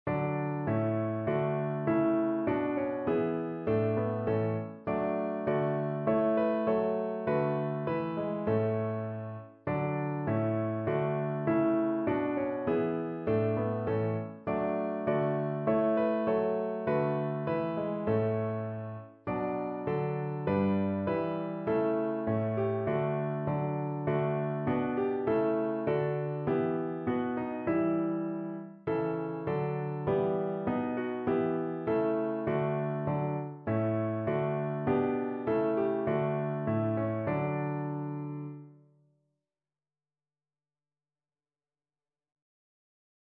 4 Stimmen gemischt
SATB